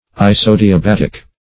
Isodiabatic \I`so*di`a*bat"ic\, a. [Iso- + Gr.